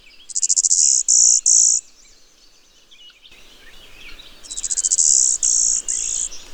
Golden-winged Warbler
Vermivora chrysoptera